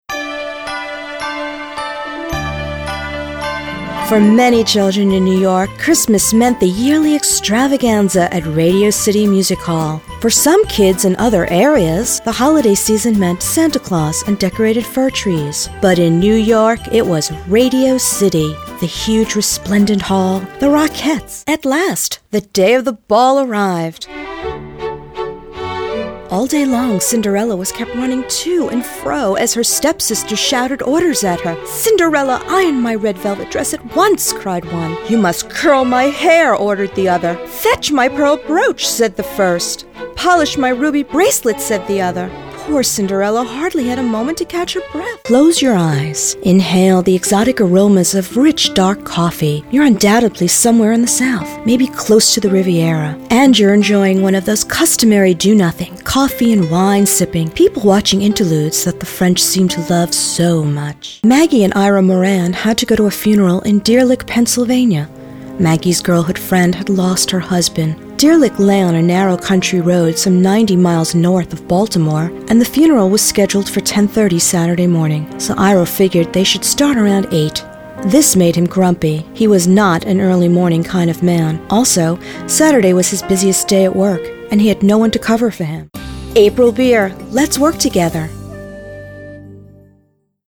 Female Voice-Over Talent
Narration Demo:
NOTE: These files are high quality stereo audio files, therefore they are large in size.